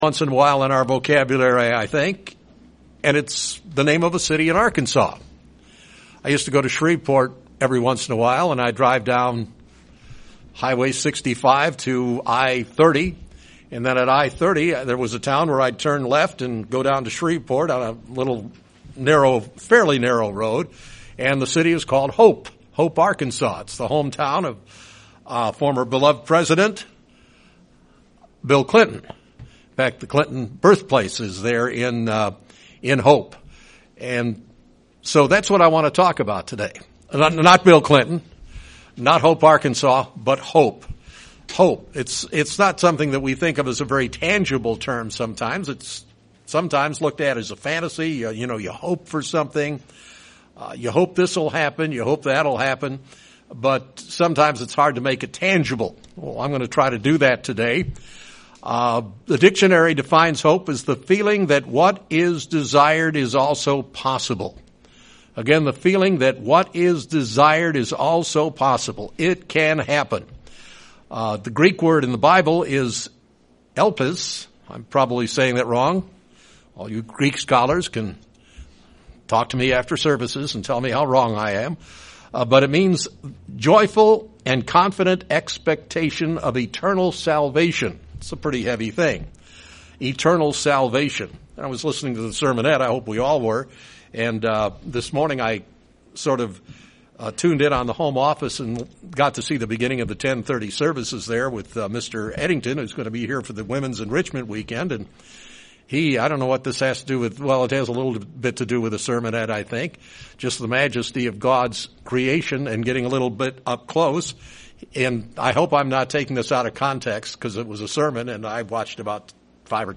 In a world steeped in chaos, Christians can find hope. This sermon addresses the topic of hope as seen through the pages of our bible.